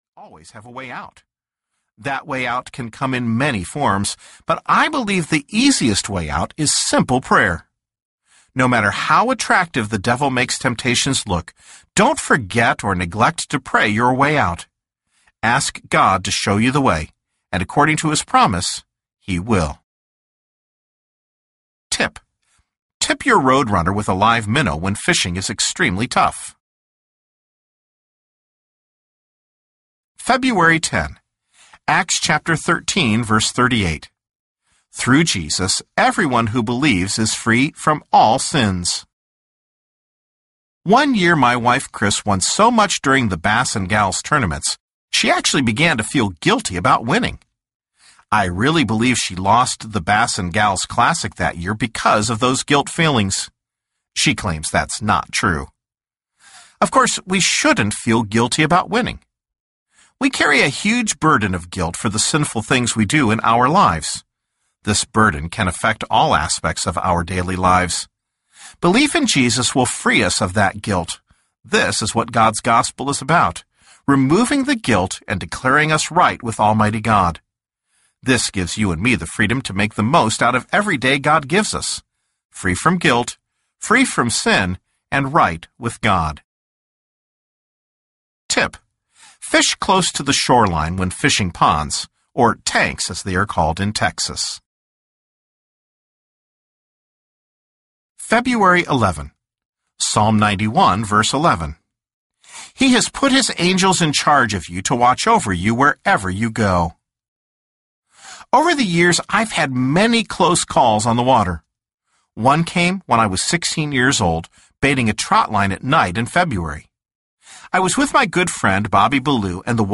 Catch of the Day Audiobook